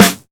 Snares
SD_Vinyl002_MPC60.wav